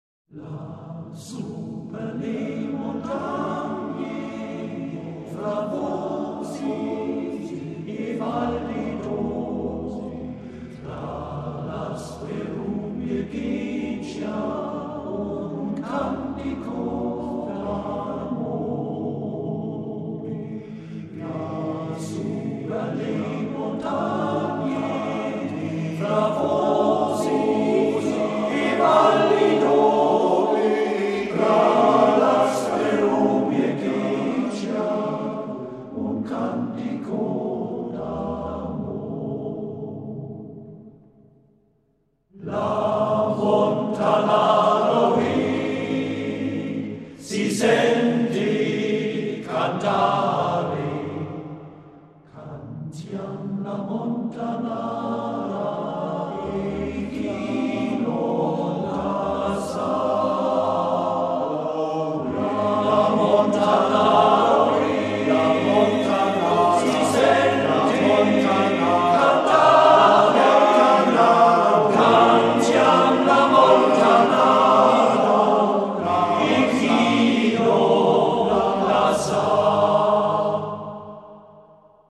A-capella-Chorgesang
1996 / Volkslieder und weltliche Chormusik